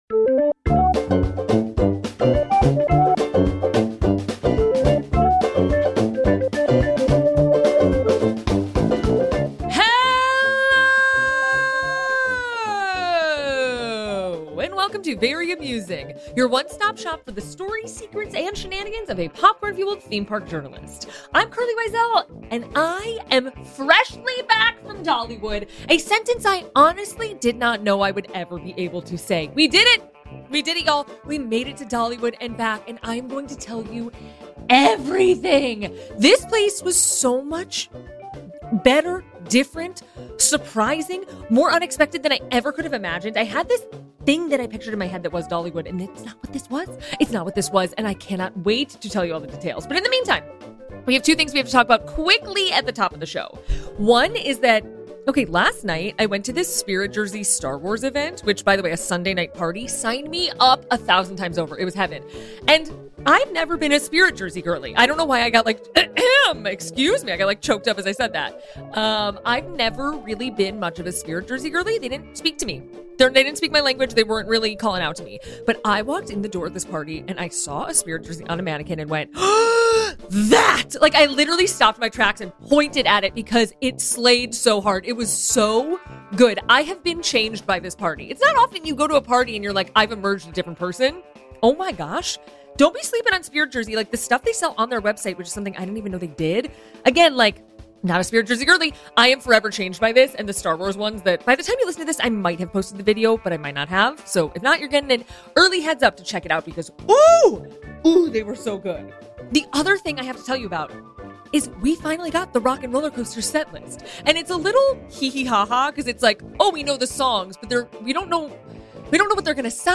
~my voice is not one hundred percent back, but I recorded this over multiple days so it should sound good!!!